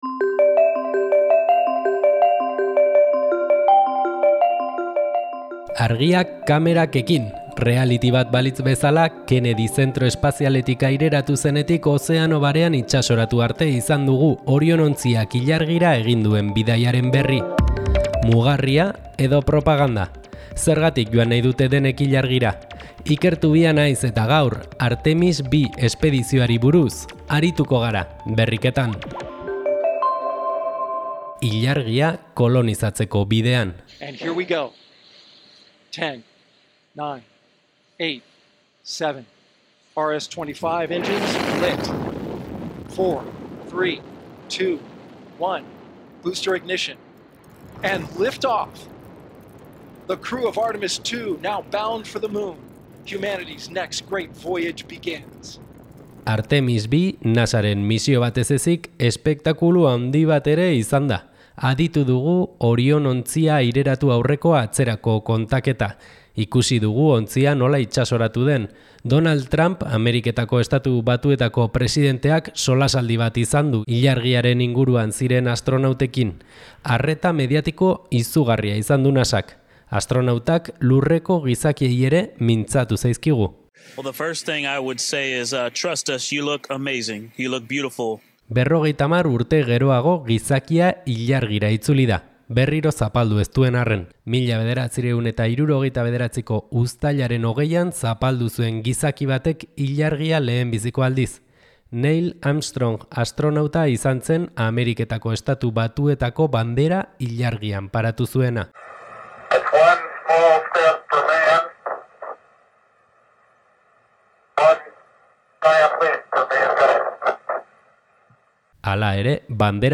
«Musikan nabil», esan du Dunbala saioan egindako elkarrizketan.